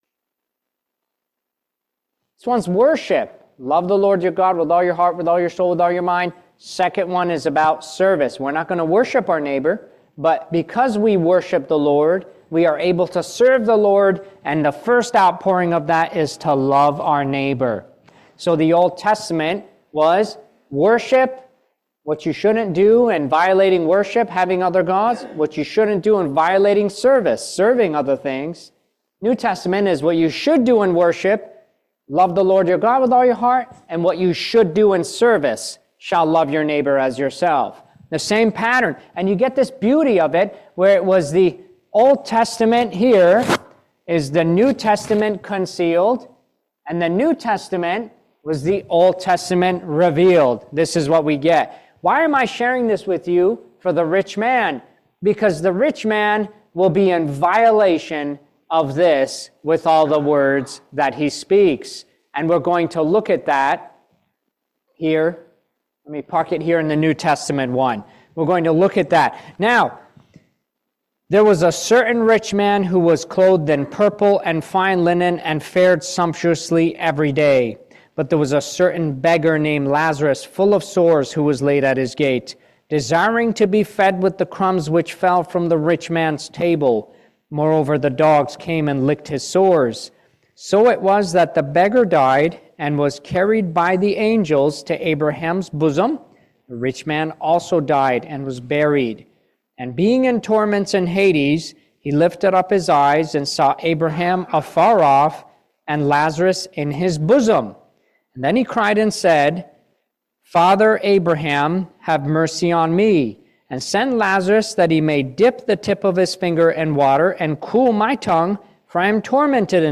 Luke 16:19-31 Service Type: Family Bible Hour Hell can’t change people